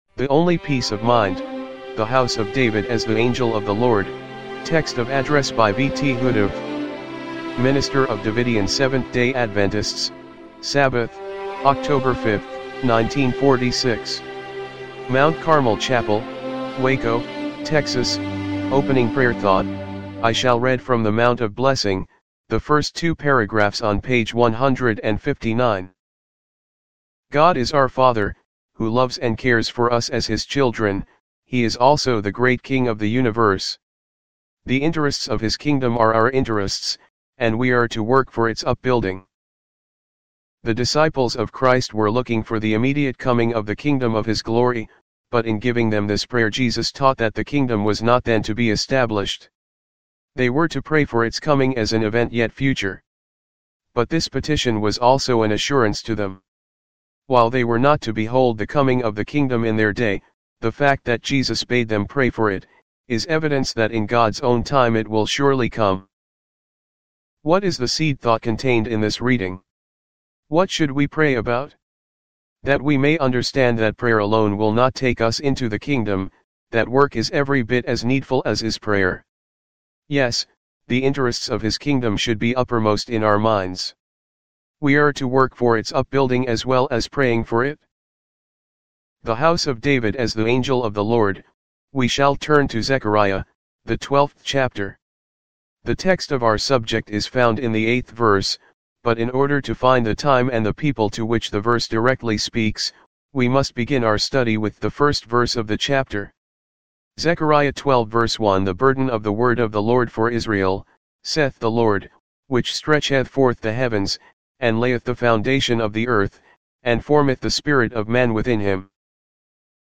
timely-greetings-volume-1-no.-9-mono-mp3.mp3